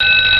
bell_school_ringing.wav